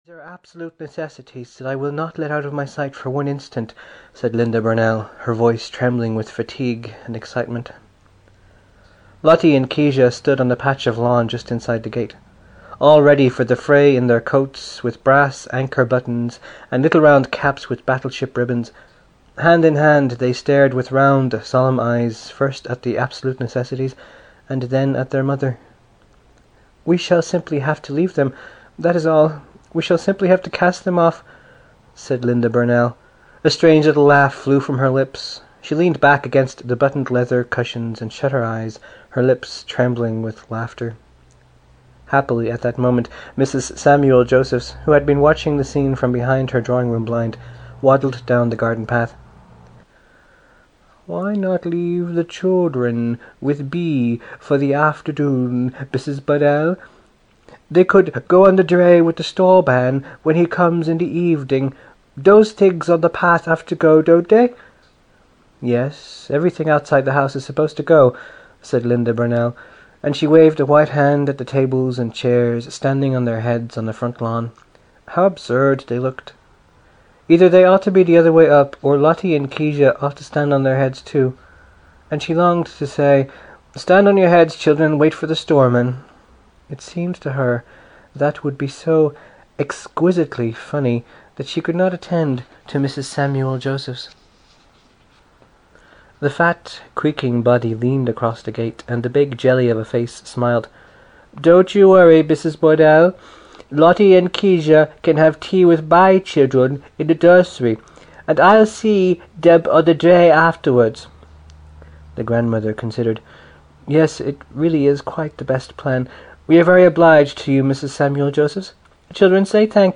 Prelude (EN) audiokniha
Ukázka z knihy